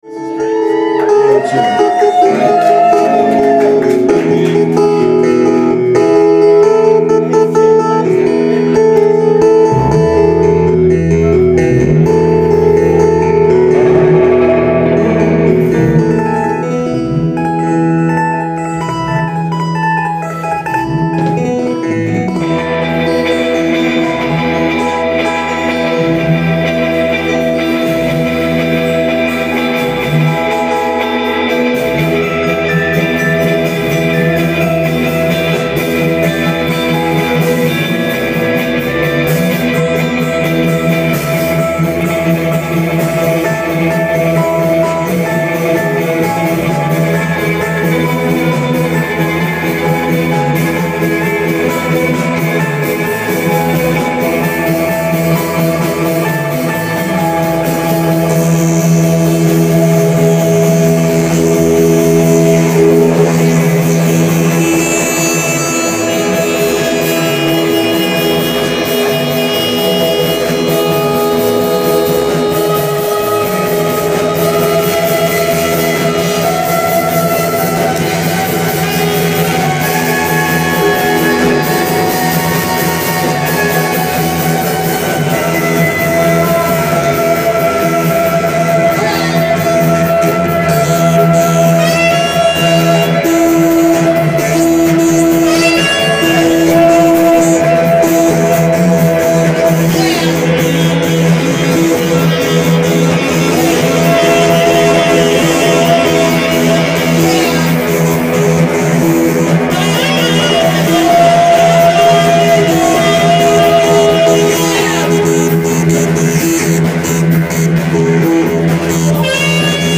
MOST MUSIC IS IMPROVISED ON SITE
keys/voice
sax/flute